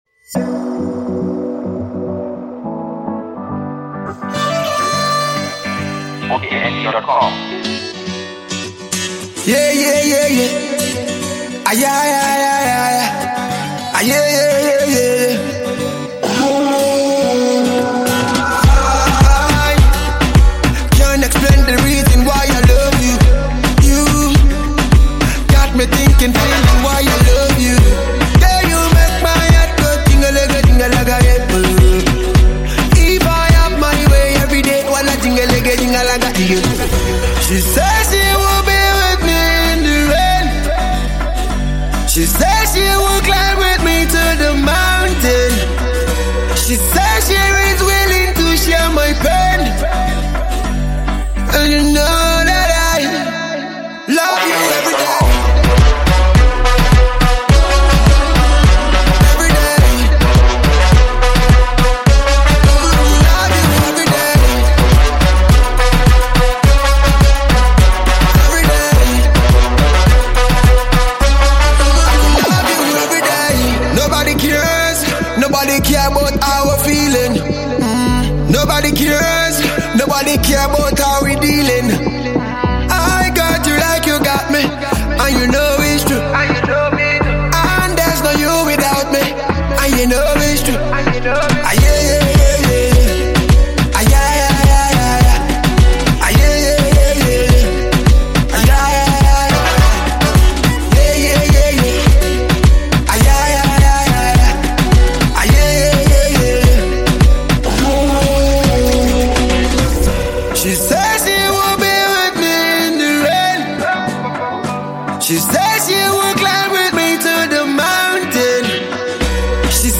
Nigerian reggae-dancehall singer and songwriter